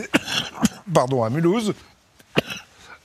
Play, download and share Jpp toux 1 original sound button!!!!
jpp-toux-1.mp3